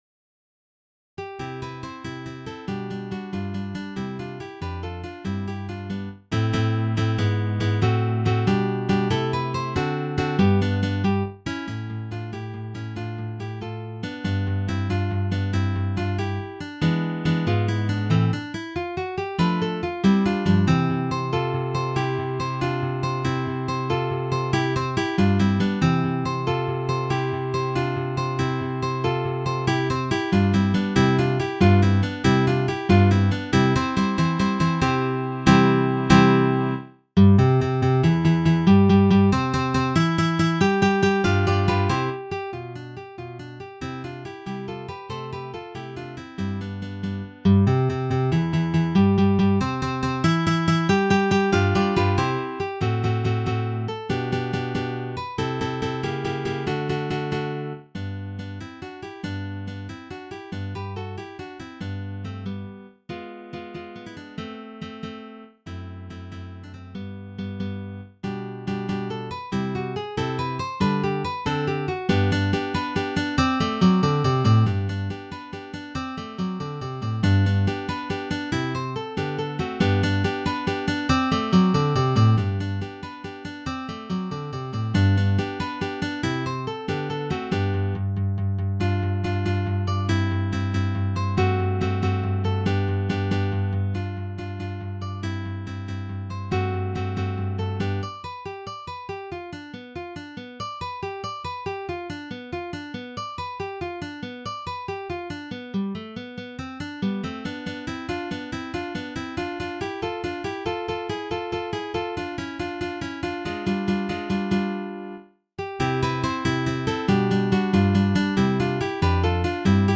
Rondo by Ferdinand Carulli is an Advanced Level duet for two guitars. The highest pitch is high G, first string, fifteenth fret. The rhythms are mostly intermediate level except for the dotted quarter-two sixteenth rhythms.